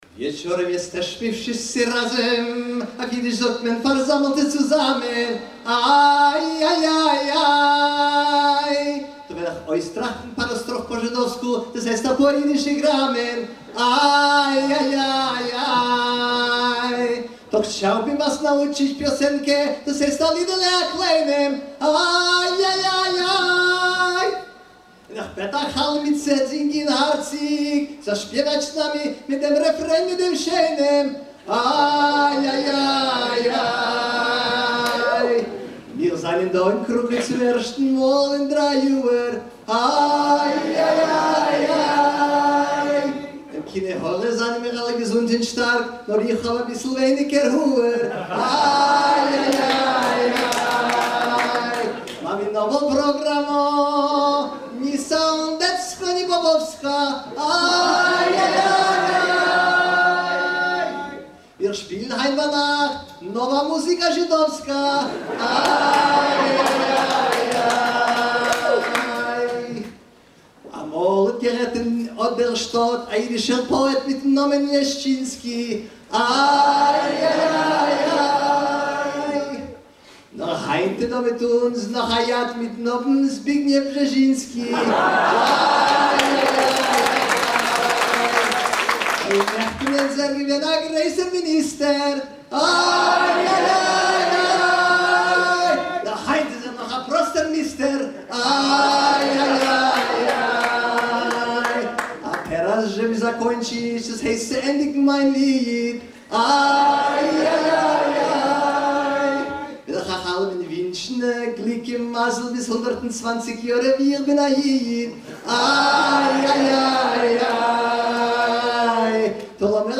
a traditional improvised sung salutation
recorded live during a concert
ma-pol-yid-badkhones-w-audience-in-krakow-1999.mp3